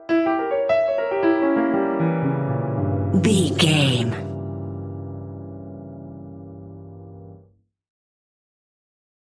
In-crescendo
Thriller
Augmented
scary
tension
ominous
dark
suspense
haunting
eerie
stinger
short music instrumental
horror scene change music